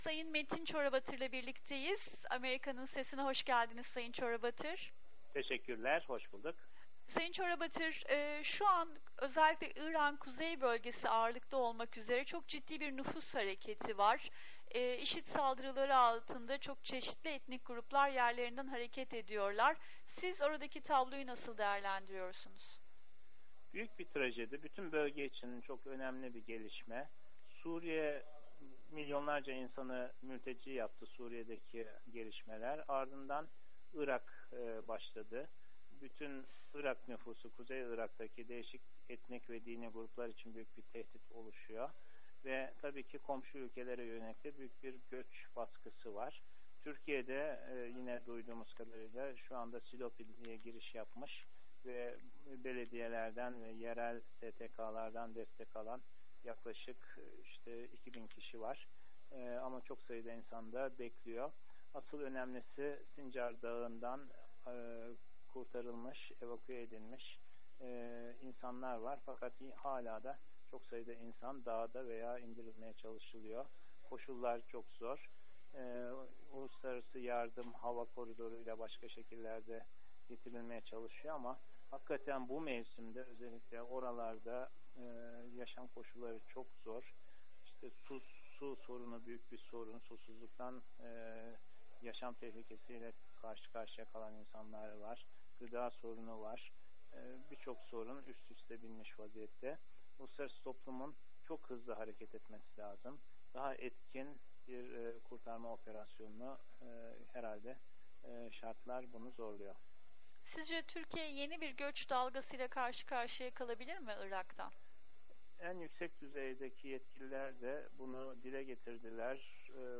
röportajı